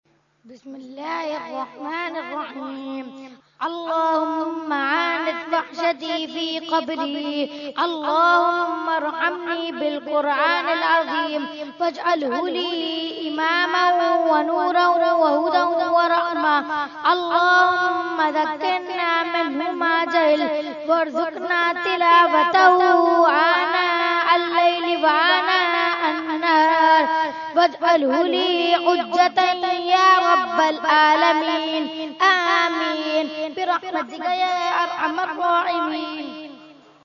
Category : Dua | Language : ArabicEvent : Khatmul Quran 2017